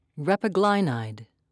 (rep-a-gli'nide)